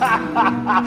"(Quaid laugh)"
quaid_laugh.mp3